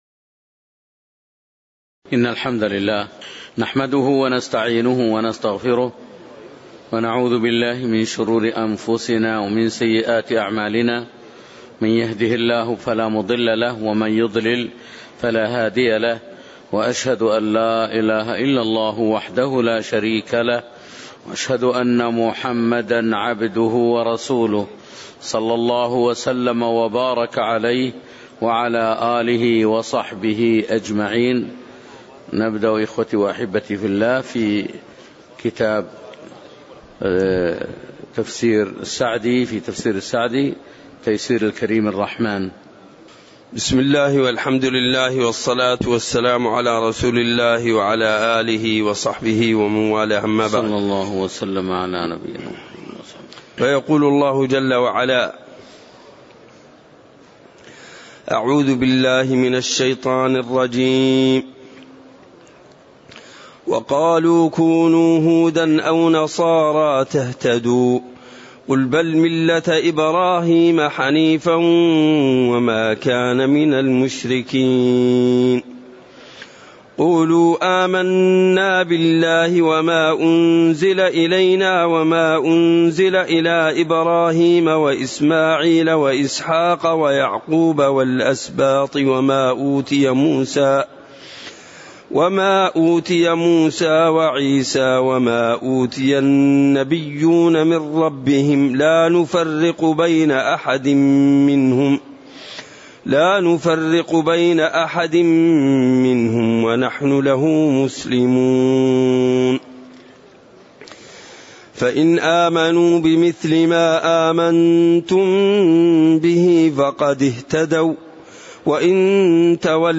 تاريخ النشر ٢٠ جمادى الأولى ١٤٣٨ هـ المكان: المسجد النبوي الشيخ